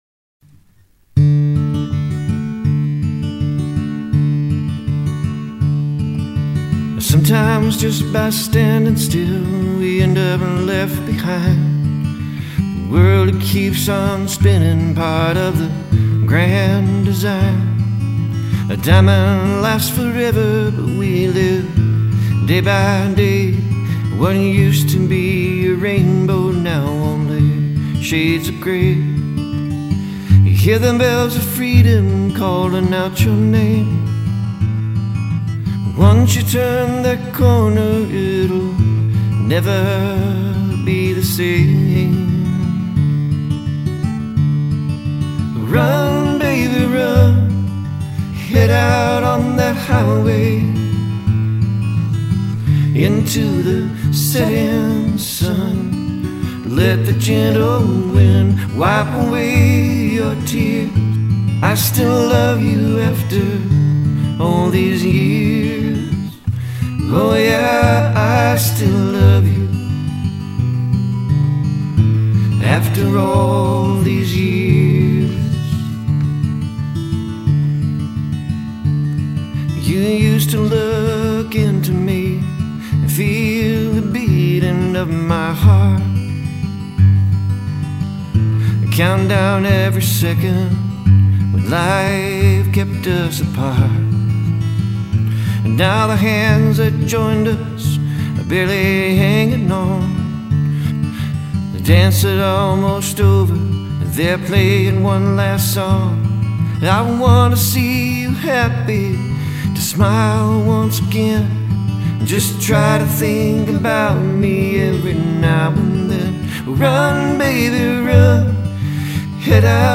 • This is the epitome of acoustic goodness.
• Very cool simple rhythm guitar opening.
Very nice folk or light country feel.
• The singer had a strong bluegrass fashion to their voice.